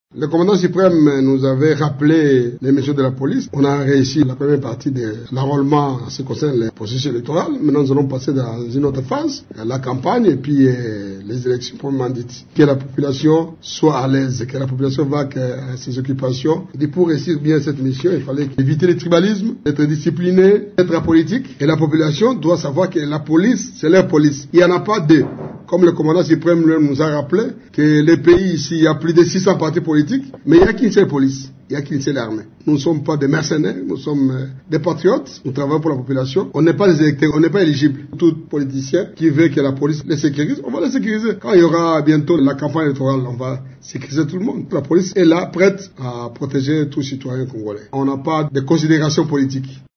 Vous pouvez écouter le général Kasongo ici :